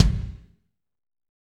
Index of /90_sSampleCDs/Northstar - Drumscapes Roland/DRM_Fast Rock/KIT_F_R Kit Wetx
KIK F R K01R.wav